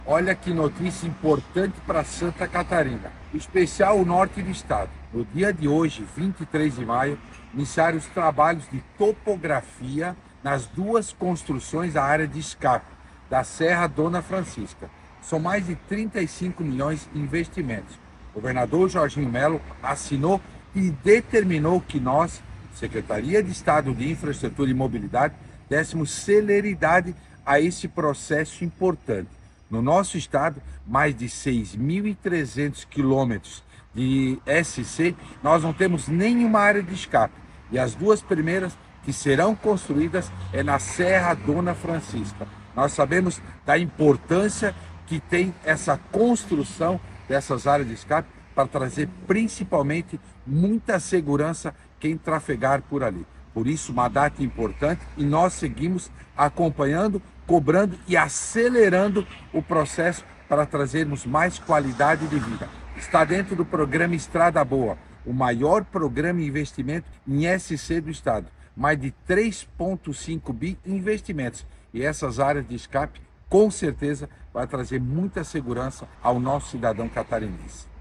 Para o secretário de Estado de Infraestrutura e Mobilidade, Jerry Comper, as áreas de escape vão trazer mais segurança a todos que trafegam pela região:
SECOM-Sonora-Secretario-Infraestrutura-Areas-Escape-Dona-Francisca.mp3